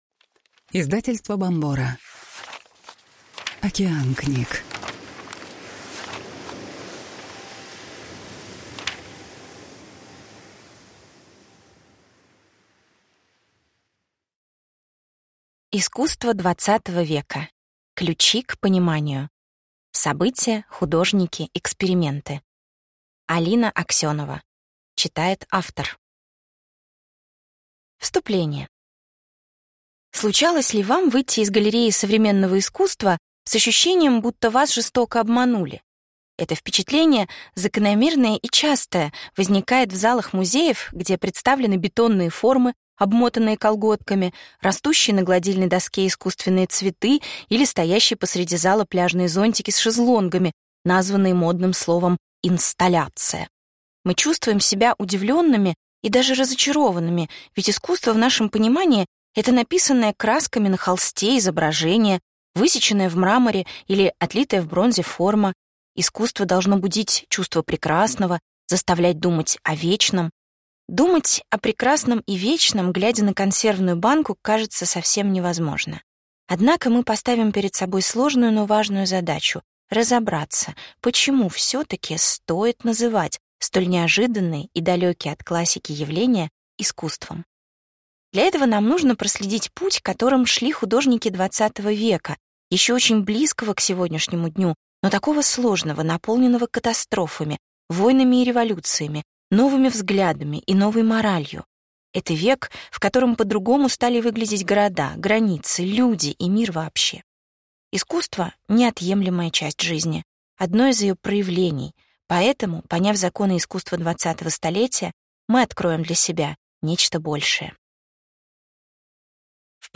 Аудиокнига Искусство XX века. Ключи к пониманию: события, художники, эксперименты | Библиотека аудиокниг